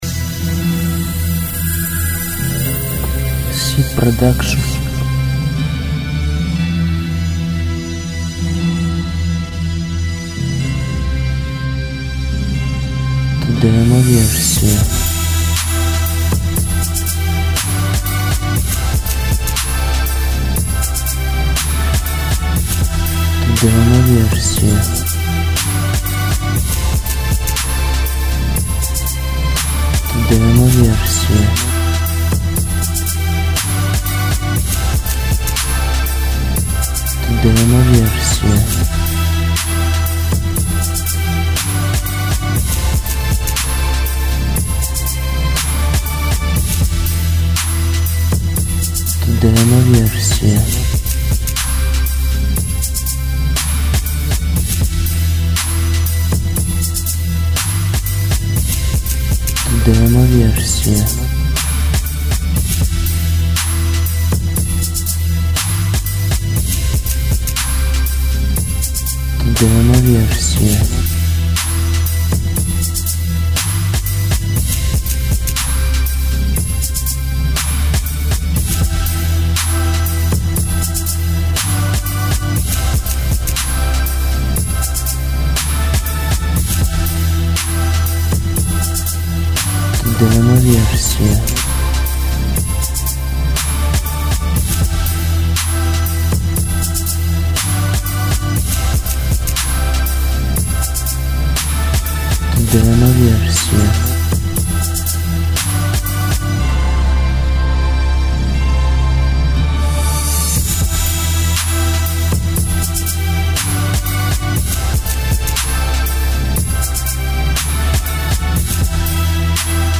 платные эксклюзивные качественные минуса